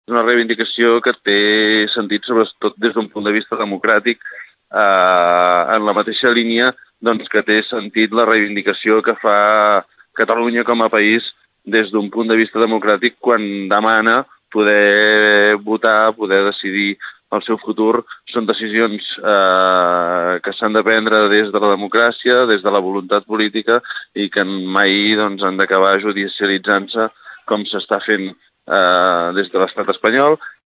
Josep Llorens és el regidor i coordinador del govern municipal.